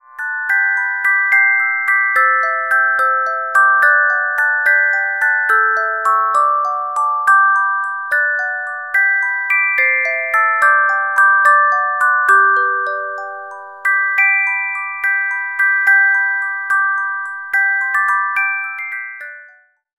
Music Box Melodies柔美音樂盒